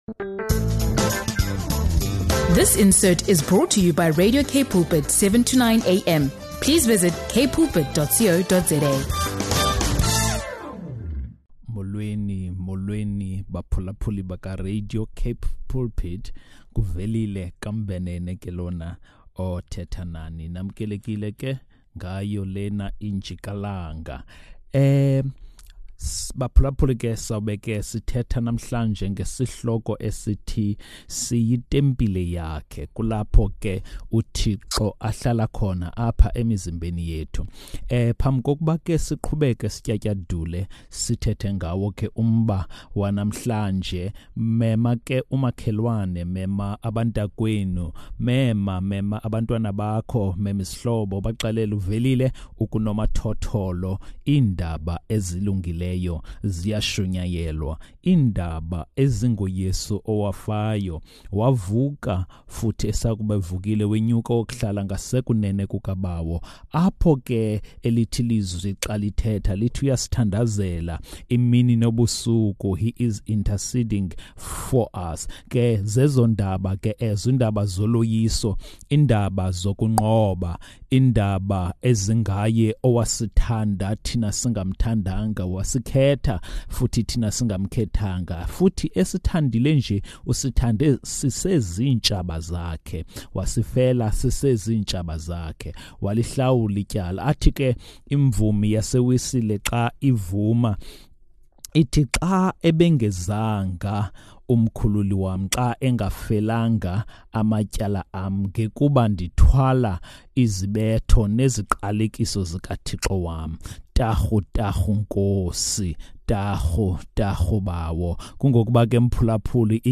In this devotional